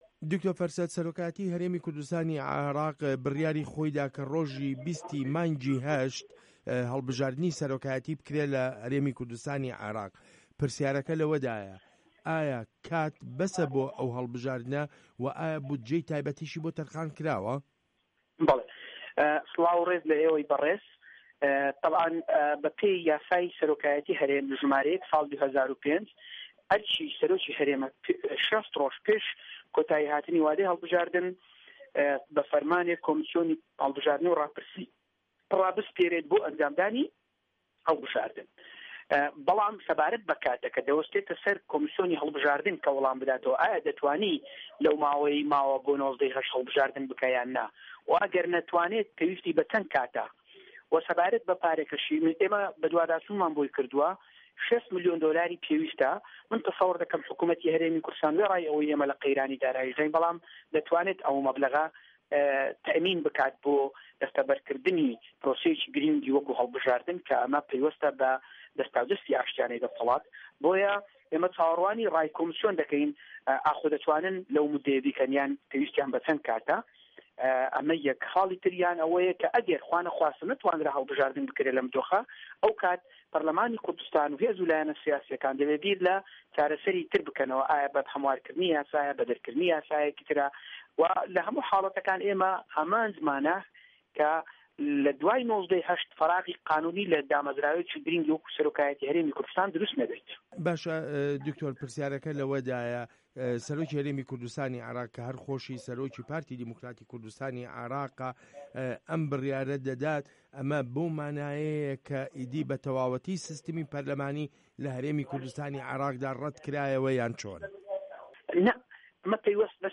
وتووێژ له‌گه‌ڵ دکتۆر فه‌رسه‌ت سۆفی